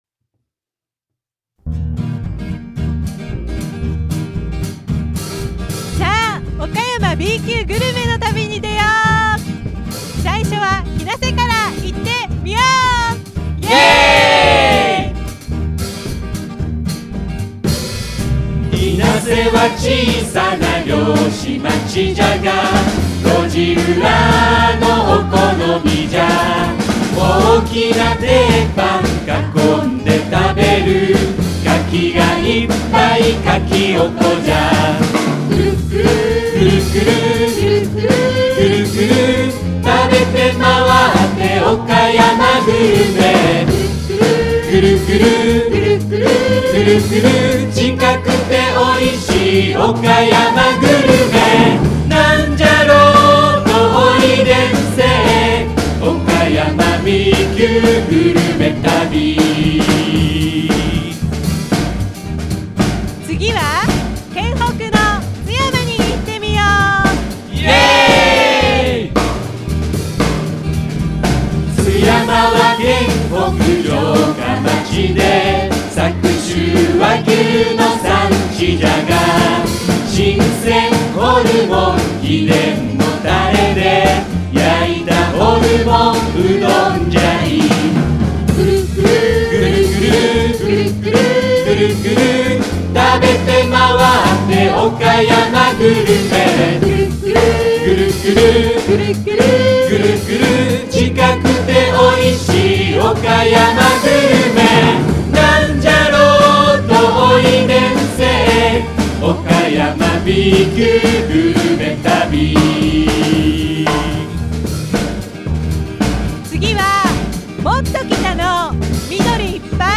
歌　日生カキオコ合唱団